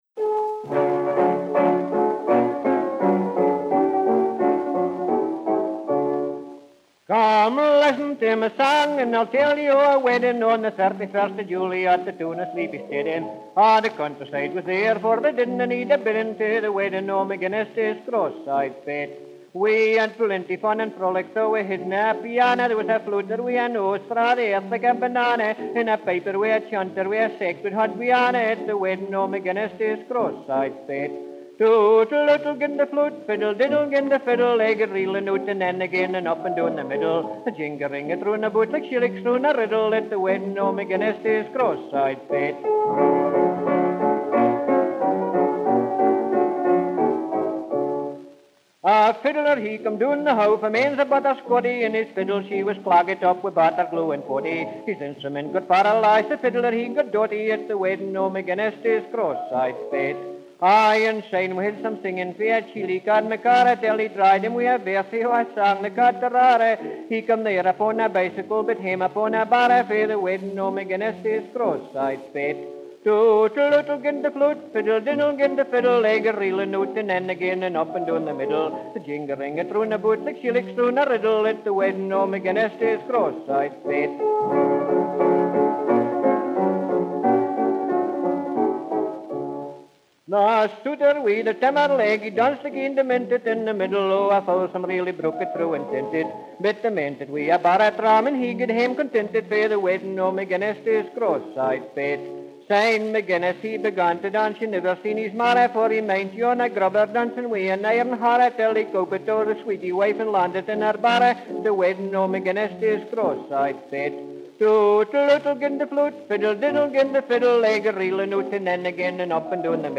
A Scottish “cornkister”
Recorded Peckham, June 1929.